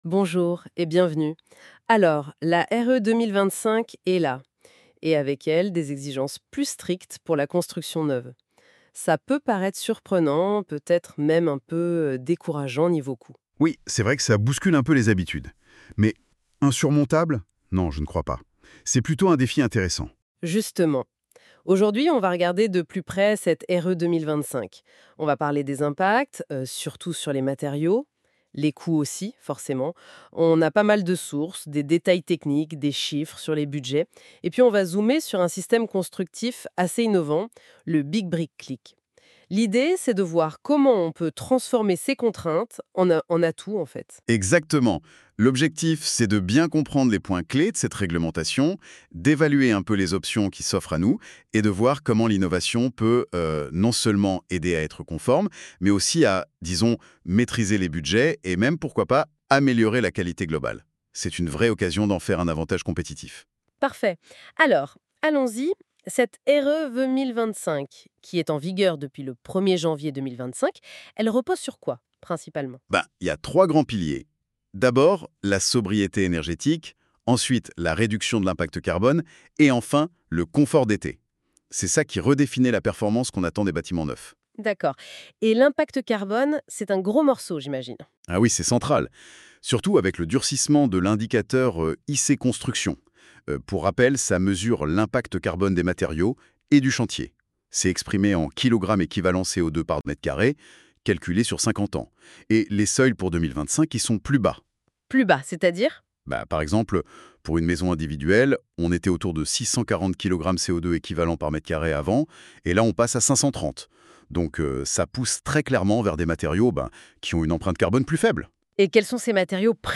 Les intervenants soulignent que, malgré un potentiel impact sur les coûts, ces nouvelles règles représentent un défi mais aussi une opportunité de transformation pour le secteur.